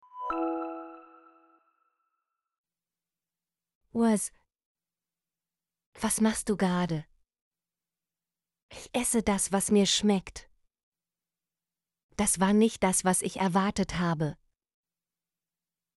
was - Example Sentences & Pronunciation, German Frequency List